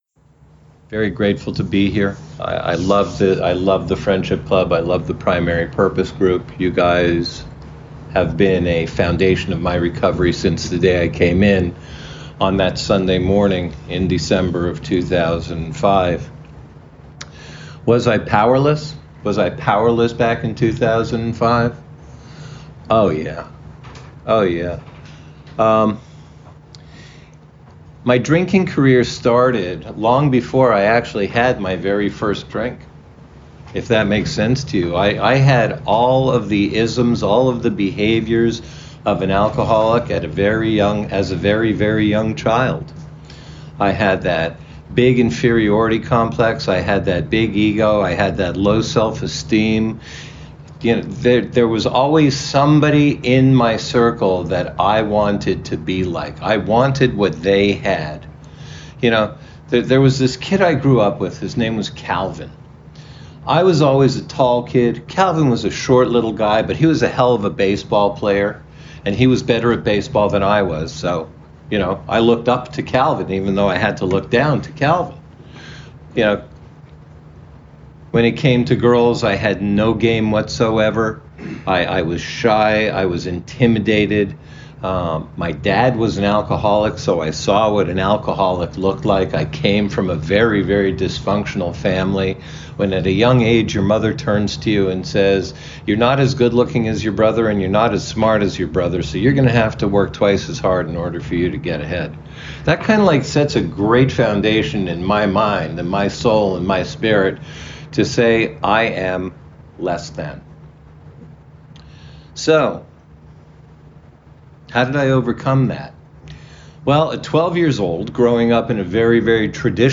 Alcoholics Anonymous Speaker Recordings